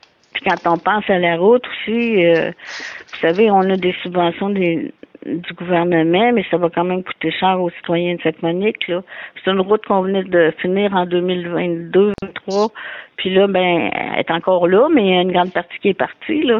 La mairesse a également déploré l’impact financier causé par ces glissements de terrain.